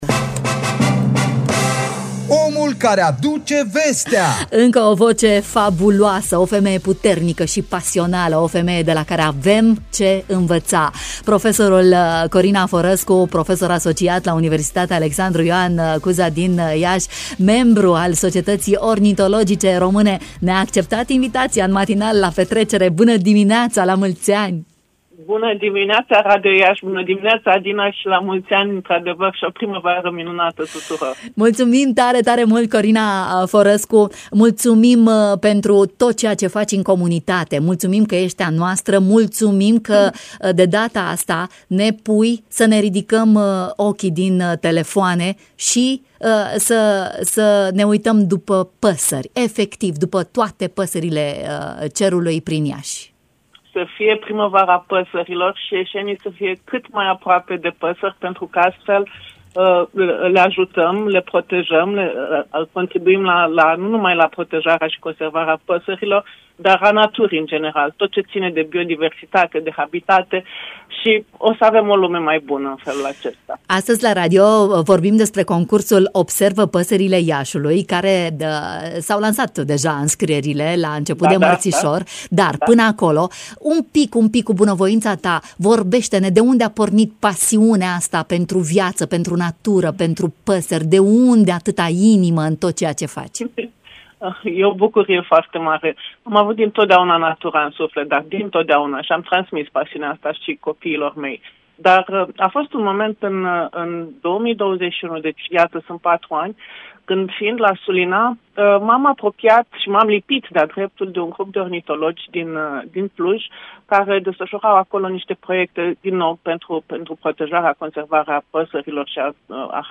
s-a auzit în matinal